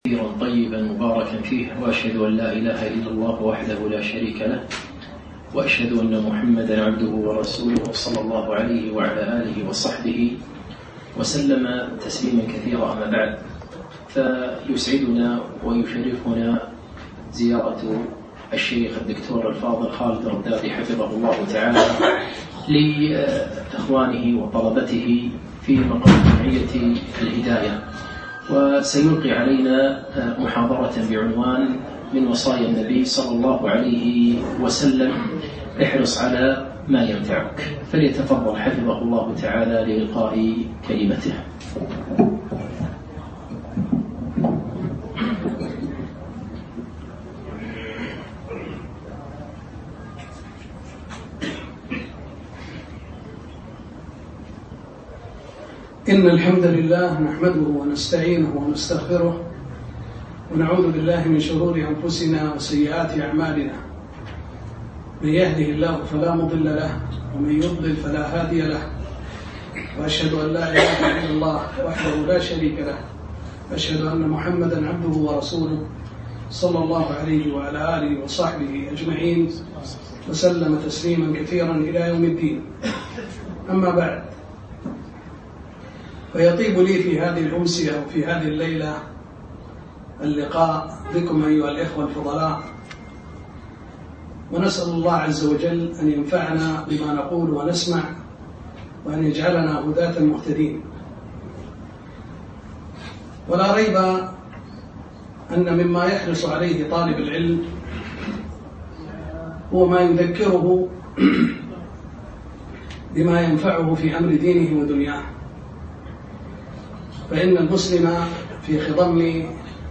محاضرة - احرص على ما ينفعك